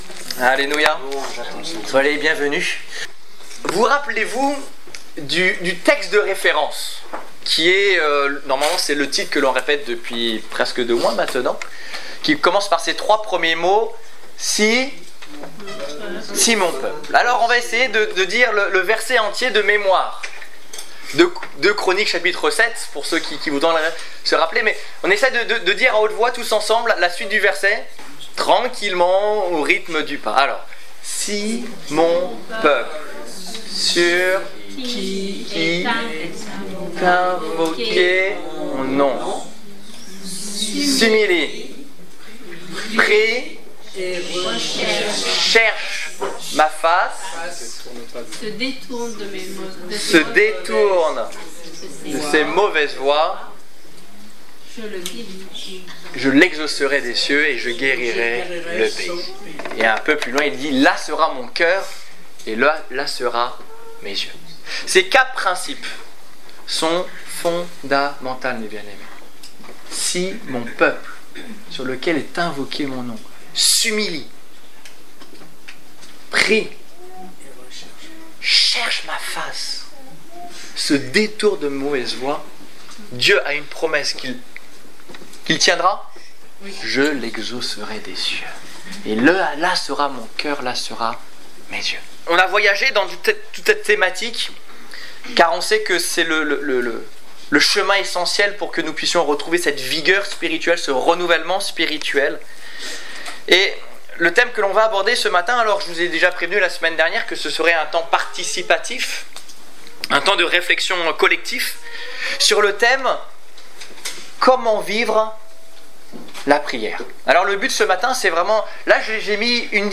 Culte du 28 décembre 2014 Ecoutez l'enregistrement de ce message à l'aide du lecteur